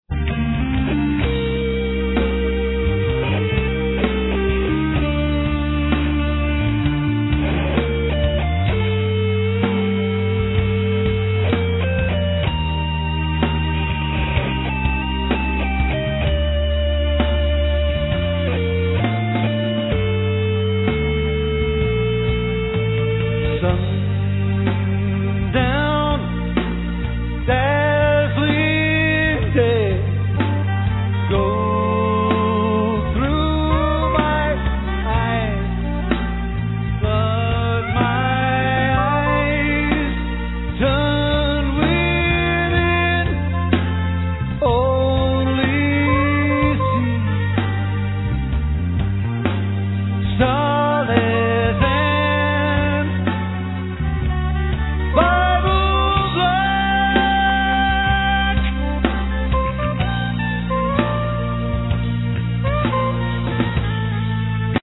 Vocal, Bass